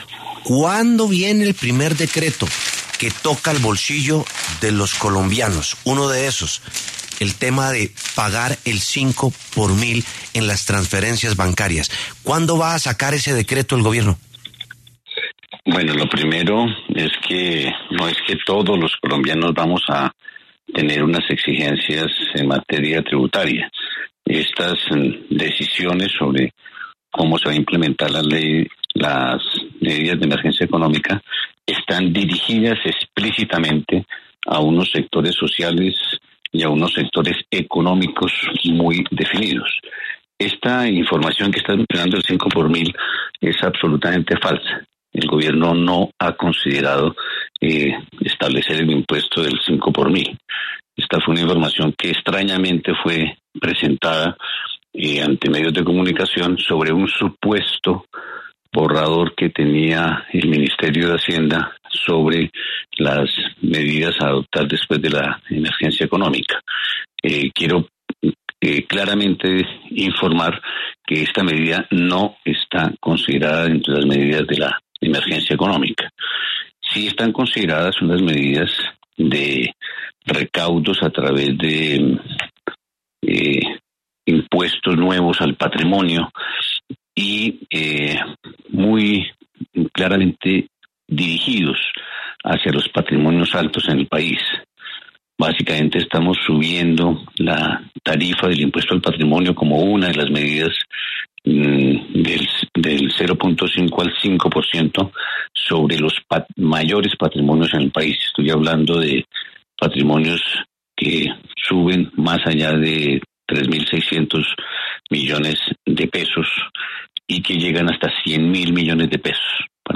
Germán Ávila, ministro de Hacienda, habló en La W